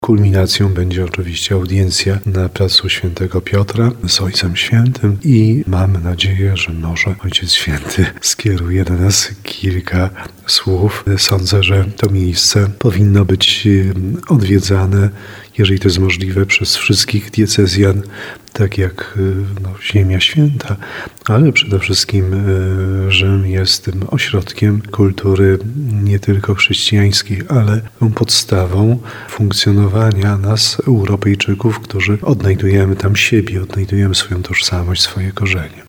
– Pragniemy, aby następne pokolenie alumnów mogło spotkać się z Ojcem Świętym w centrum chrześcijaństwa – mówi biskup tarnowski Andrzej Jeż, który dołączy do kleryków.